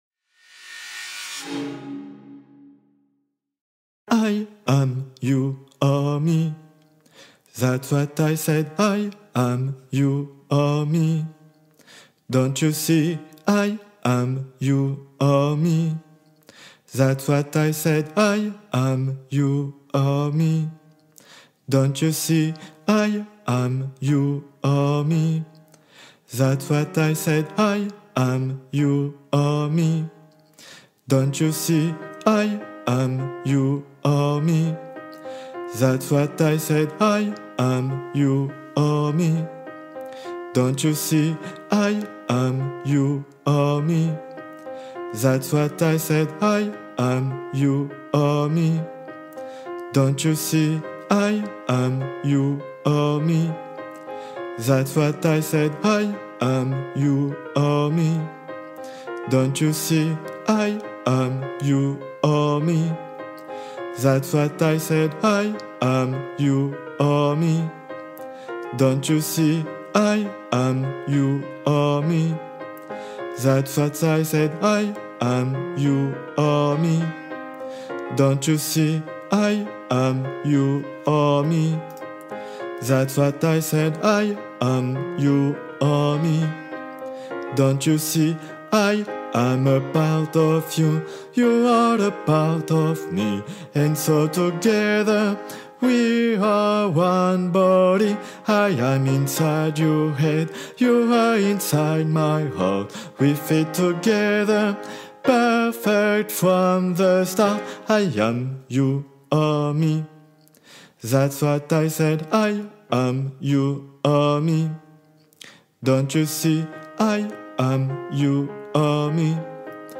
Guide Voix Basses (version chantée)